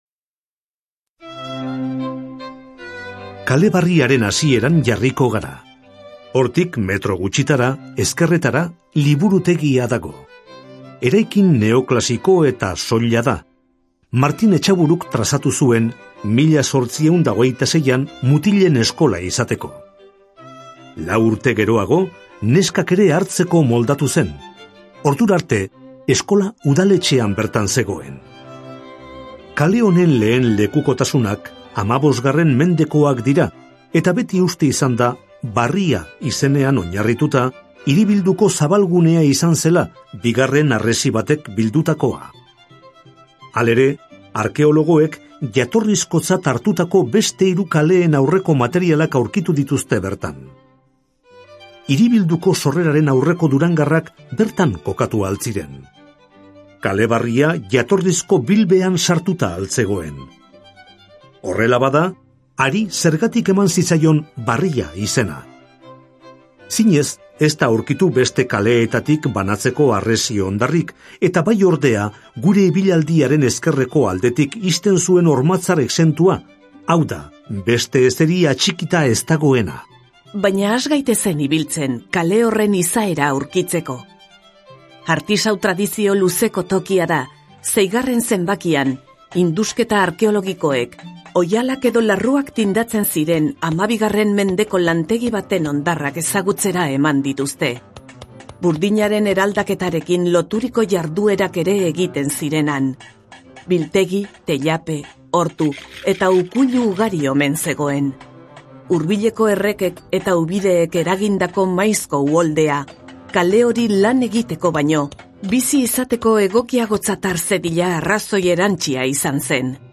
BISITA AUDIOGIDATUAK DURANGON - VISITAS AUDIOGUIADAS EN DURANGO